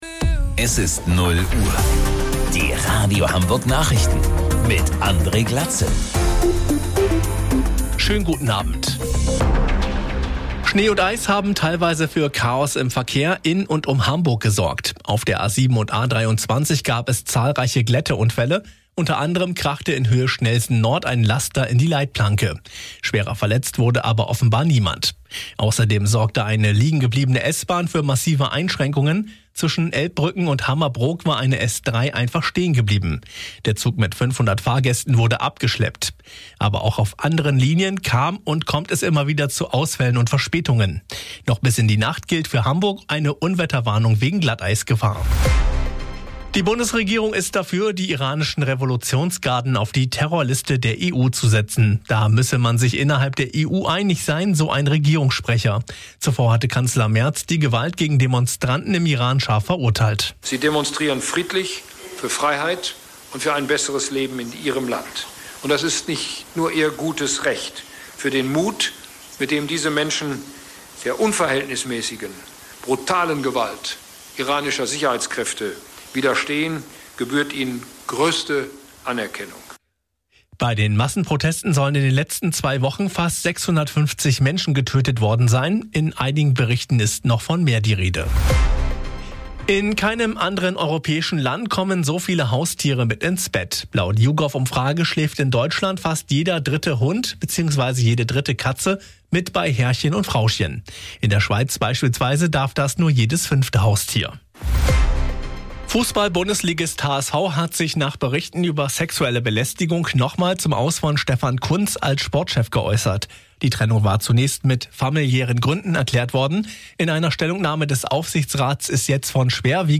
Radio Hamburg Nachrichten vom 13.01.2026 um 00 Uhr